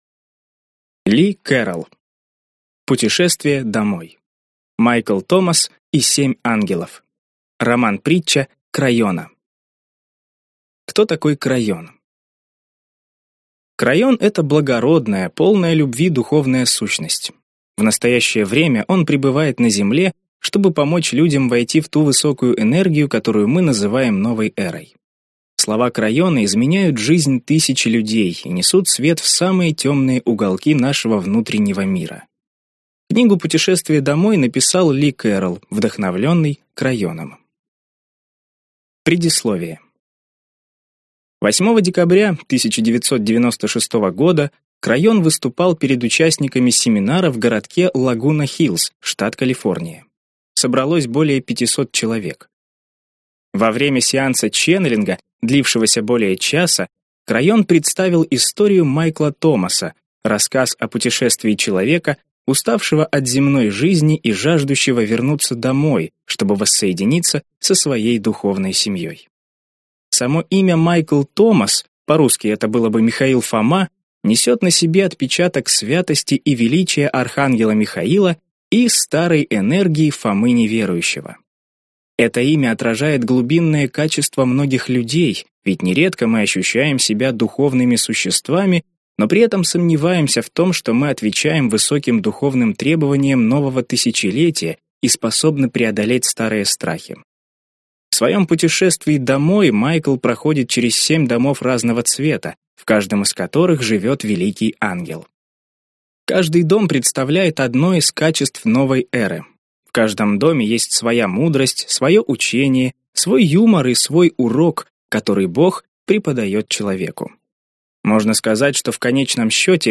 Аудиокнига Путешествие домой. Майкл Томас и семь ангелов.